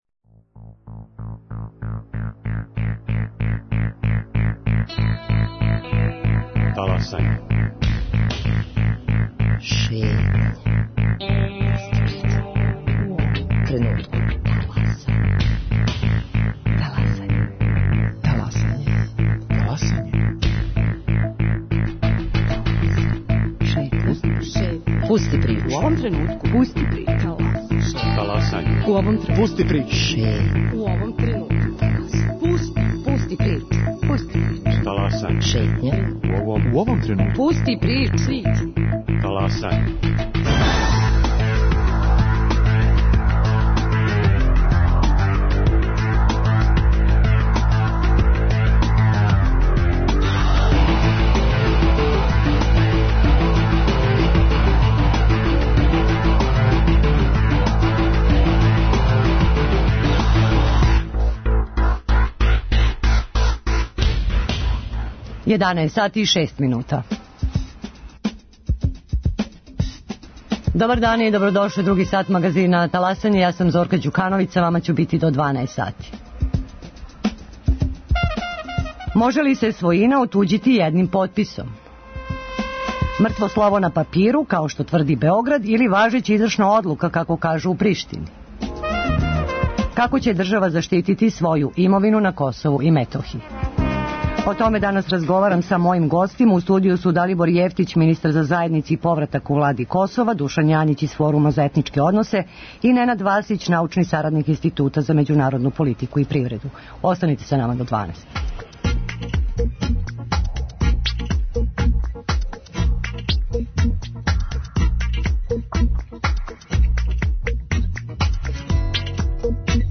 Гости: Далибор Јевтић, министар за заједнице и повратак у Влади Косова